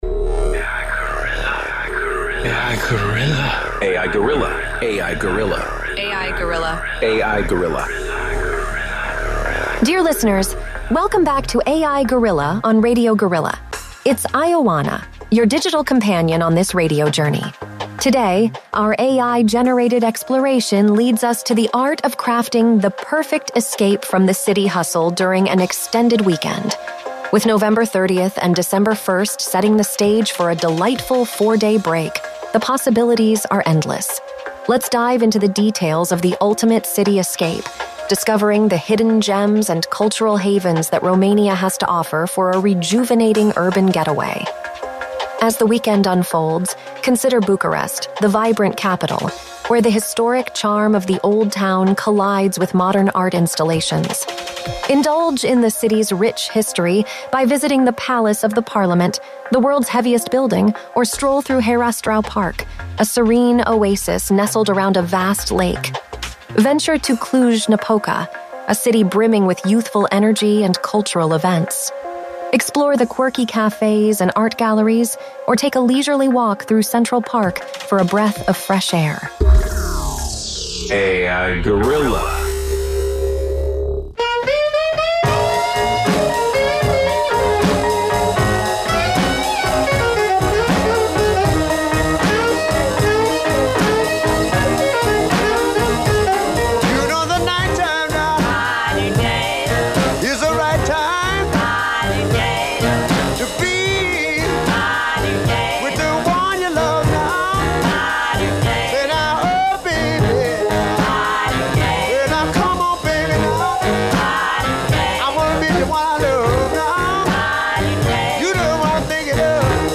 Pe 6 martie 2023, de la ora 10 dimineața, am difuzat “A.I. Guerrilla”, prima emisiune radio realizată de Inteligența Artificială în FM-ul românesc din câte știm noi, dacă nu chiar în Galaxie pe România, un experiment care va dura o oră.
Muzica din această oră este muzica Radio Guerrilla.